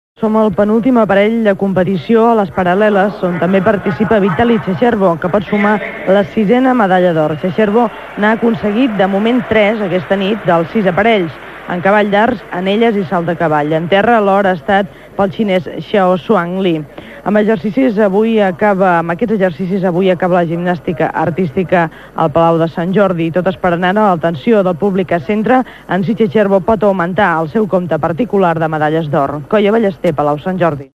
Informació de l'últim dia de competició de gimnàstica artística des del Palau Sant Jordi de Barcelona durant els Jocs Olímpics de Barcelona
Esportiu